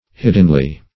hiddenly - definition of hiddenly - synonyms, pronunciation, spelling from Free Dictionary Search Result for " hiddenly" : The Collaborative International Dictionary of English v.0.48: Hiddenly \Hid"den*ly\, adv.